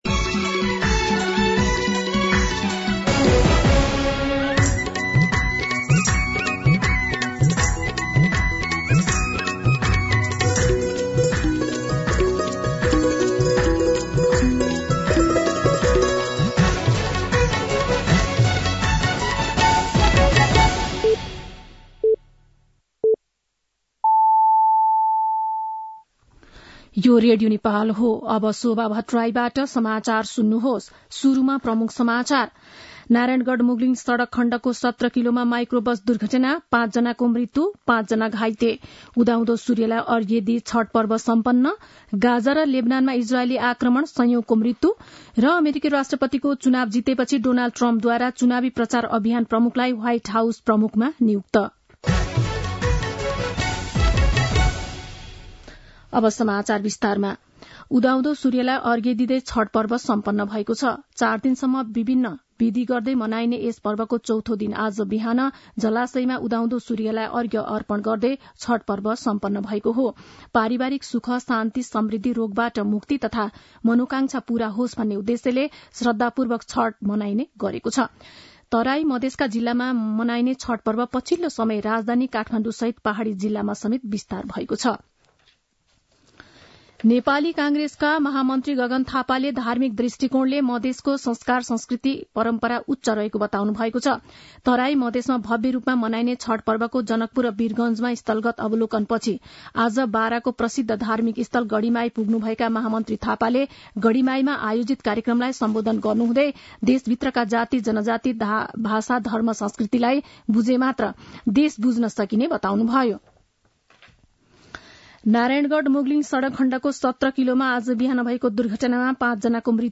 दिउँसो ३ बजेको नेपाली समाचार : २४ कार्तिक , २०८१